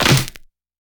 Dilla L Clap 15.wav